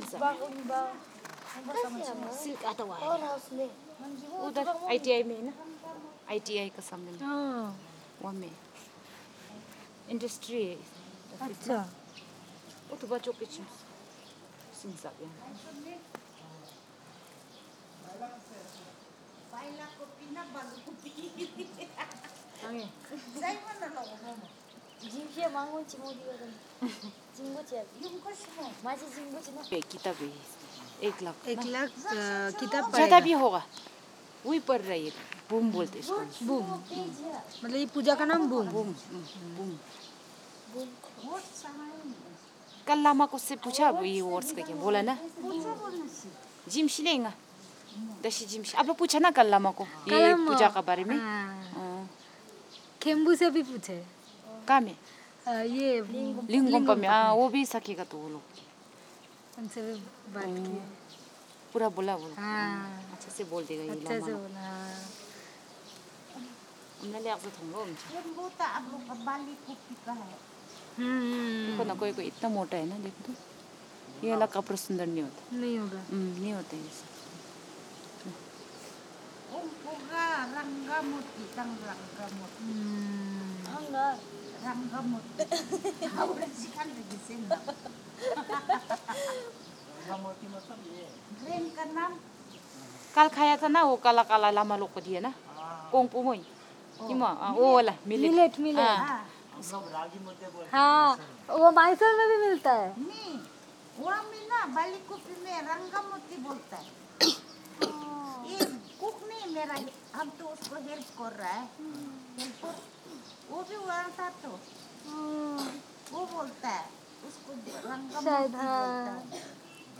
Conversation about millet and an insect